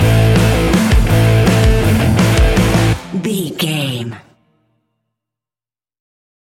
Ionian/Major
A♭
hard rock
heavy metal
instrumentals